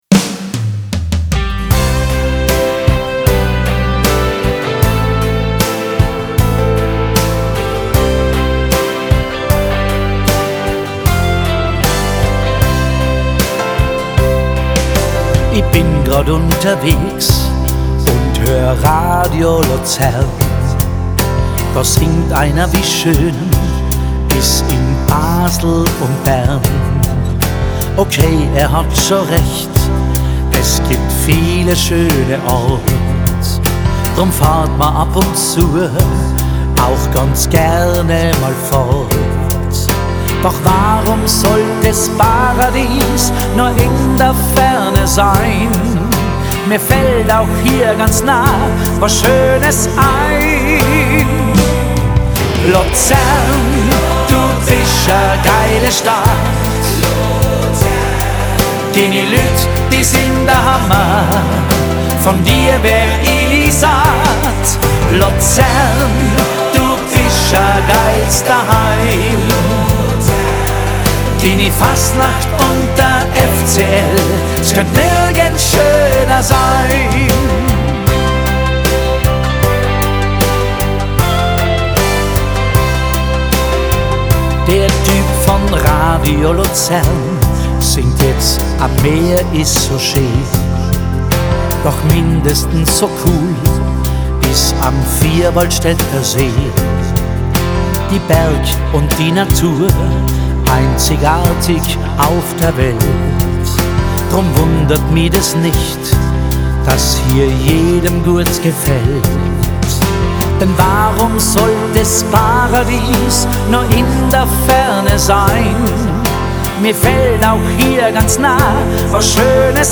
gesungen auf Schweizerdeutsch mit leichtem Tiroler Akzent.